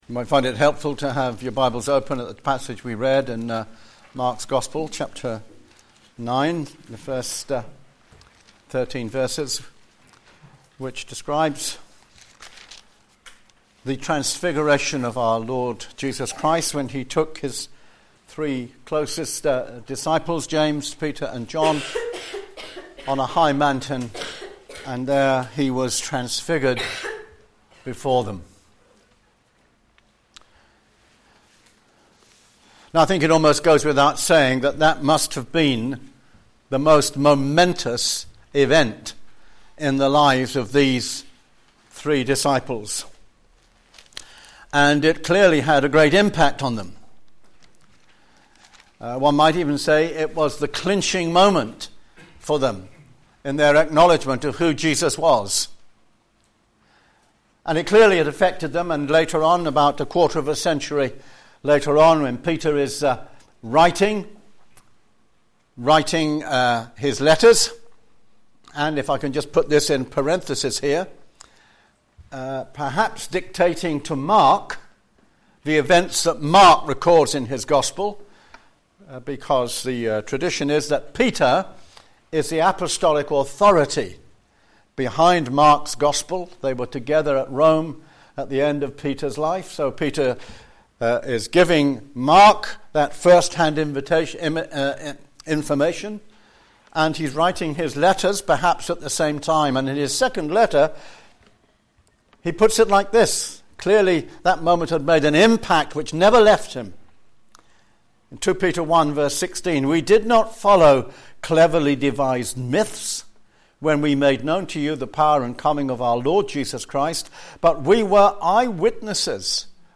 a.m. Service
The Transfiguration Sermon